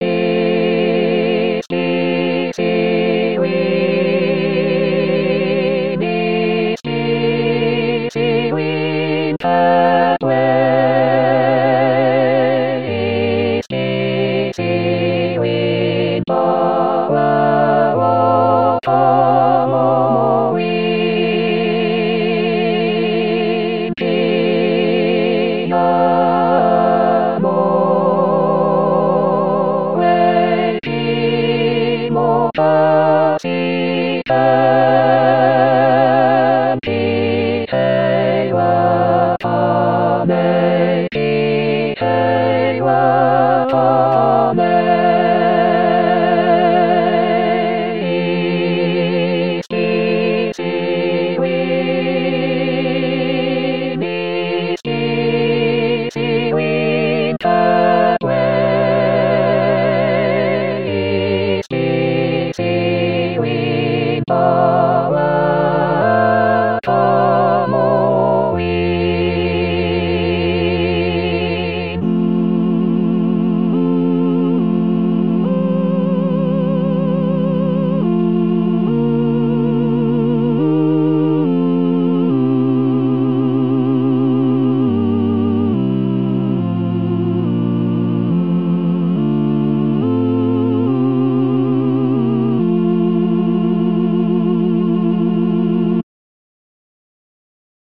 Full choir